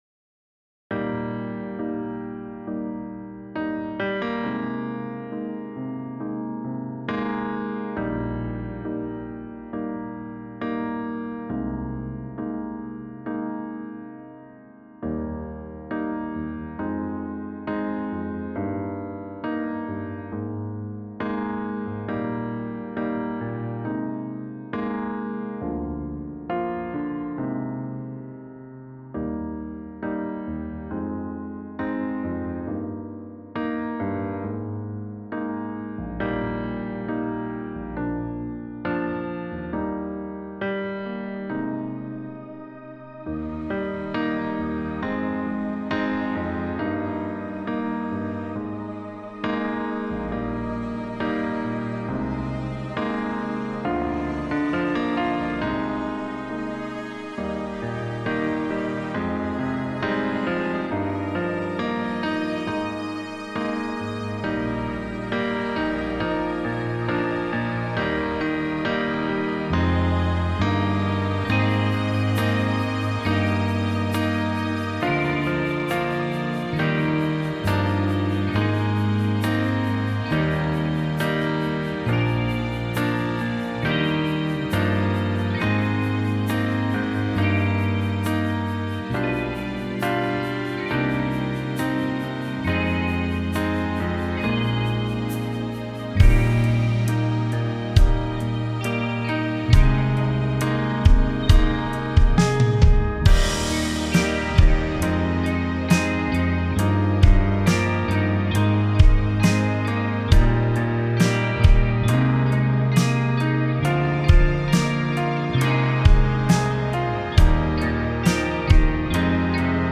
Key: E BPM: 68 Time sig: 4/4 Duration:  Size: 9MB
Contemporary Worship